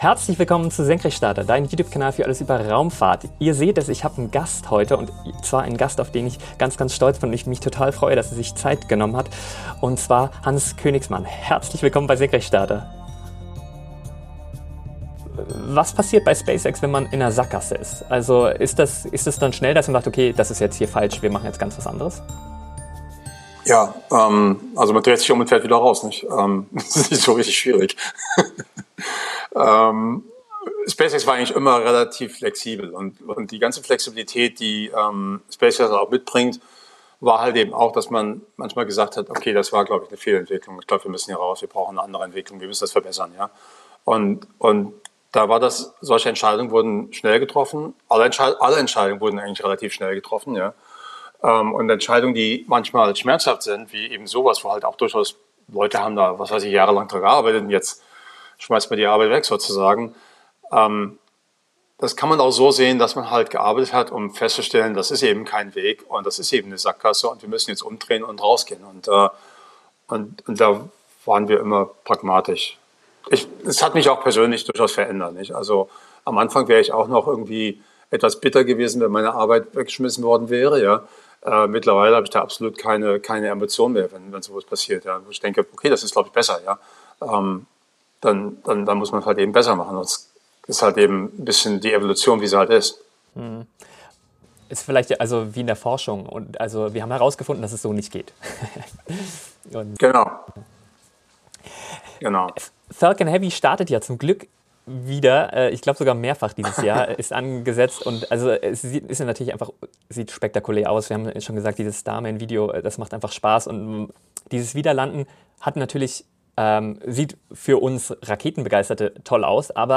Im 3. Teil des Interviews